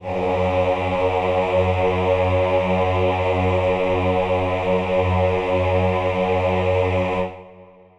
Choir Piano (Wav)
F#2.wav